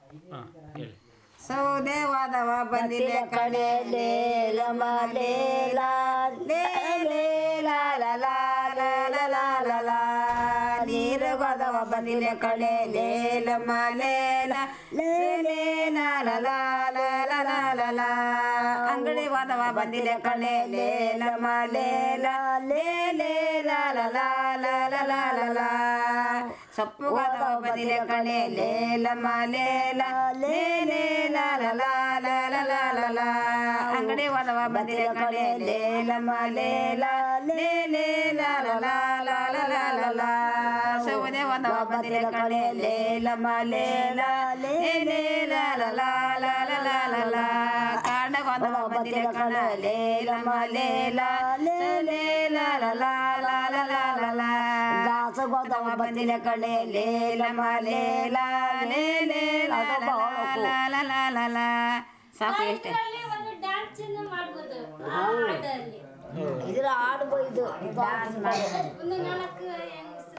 Performance of a song about wood collection